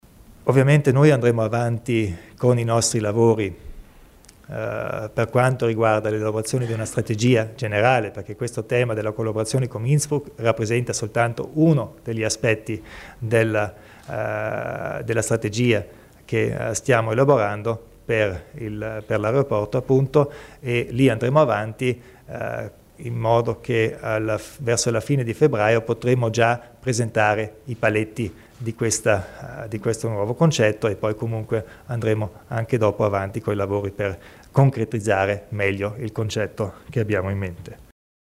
Il Presidente Kompatscher illustra i progetti per il futuro dell'aeroporto di Bolzano